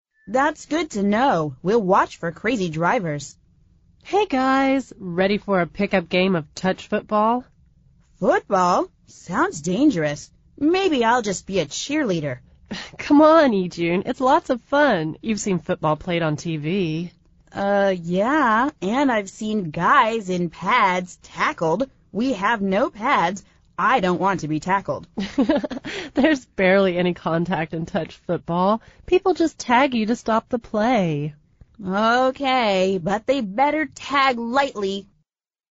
美语会话实录第207期(MP3+文本):It's lots of fun!